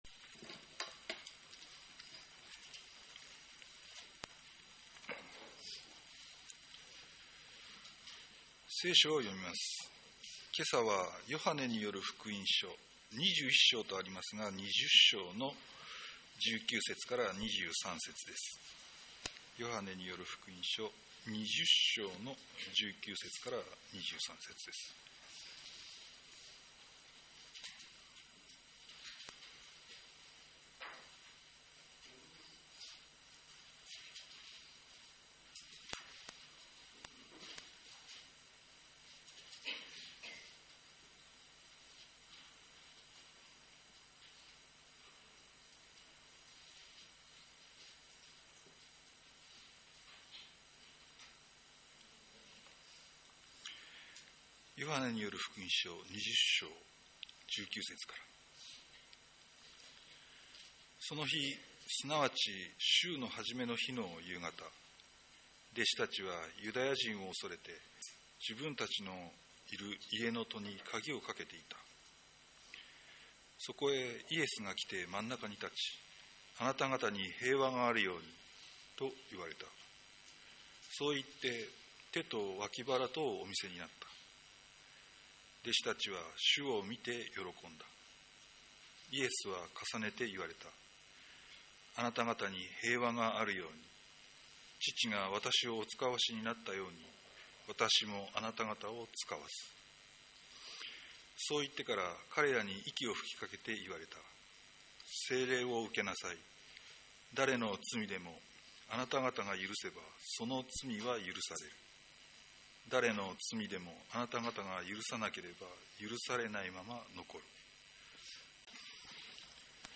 ■ ■ ■ ■ ■ ■ ■ ■ ■ 2025年4月 4月6日 4月13日 4月20日 4月27日 毎週日曜日の礼拝で語られる説教（聖書の説き明かし）の要旨をUPしています。